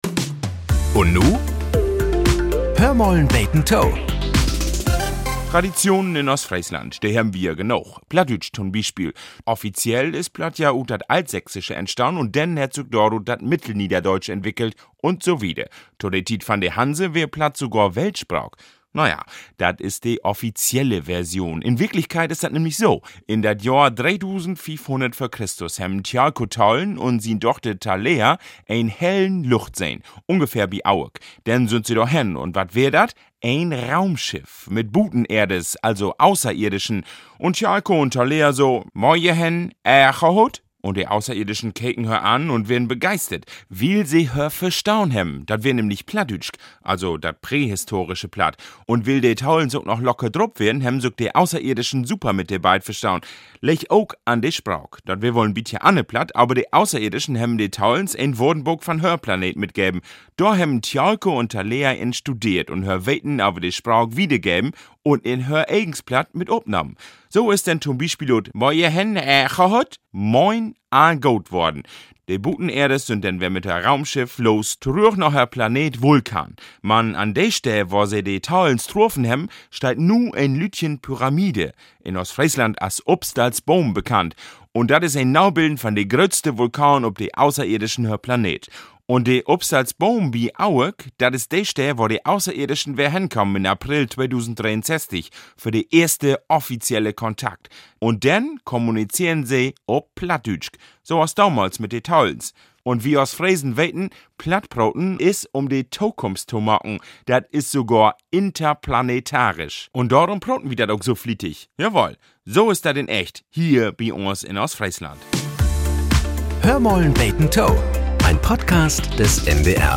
Nachrichten - 10.01.2025